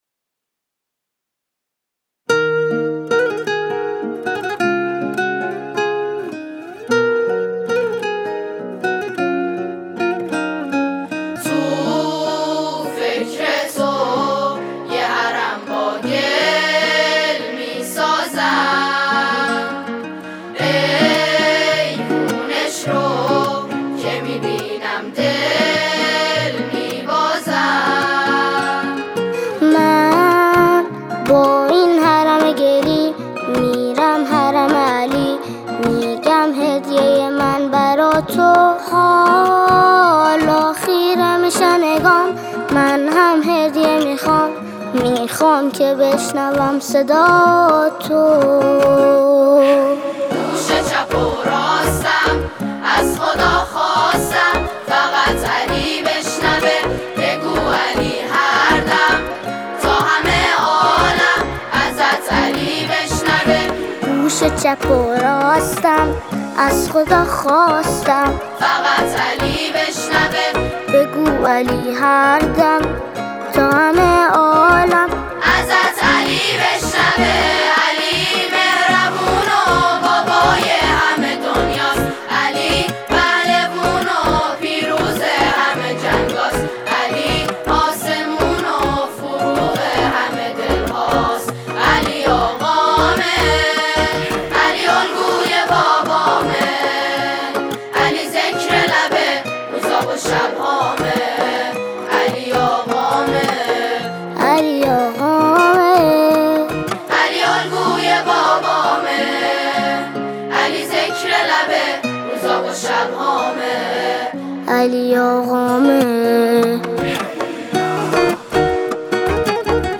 گروه سرود یزد